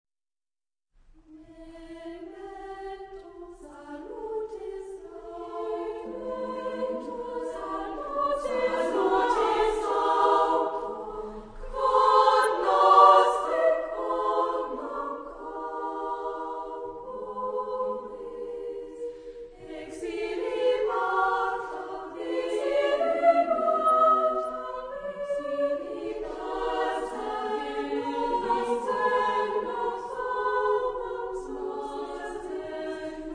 Genre-Style-Form: Motet ; Sacred
Tonality: A major ; A minor ; aeolian
Discographic ref. : 4.Deutscher Chorwettbewerb, 1994